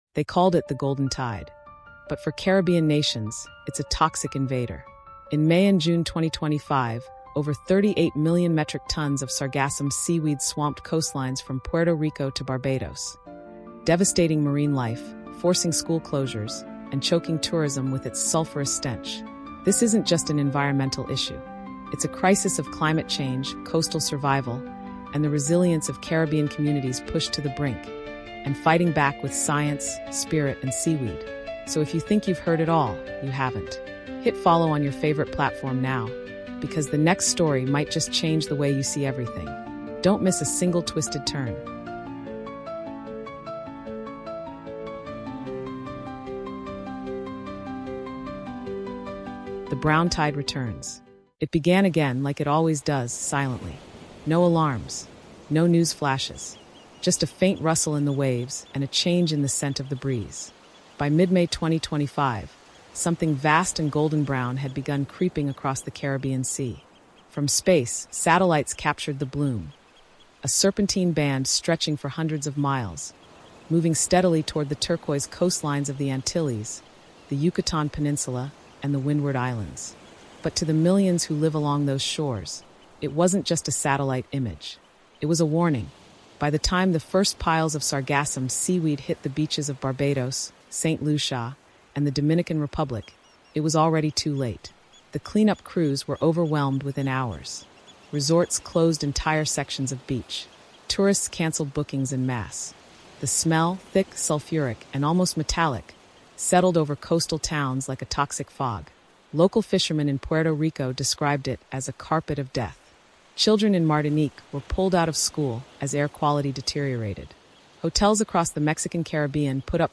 Dive into the Sargassum seaweed crisis gripping the Caribbean, where over 38 million metric tons of seaweed are choking coastlines from Puerto Rico to Martinique. In this powerful Caribbean History documentary, we explore how this environmental emergency intersects with tourism, climate change, and Caribbean identity. Join local voices, history experts, scientists, and Indigenous leaders as they unpack the crisis’ deep roots — from colonization to modern ecological neglect.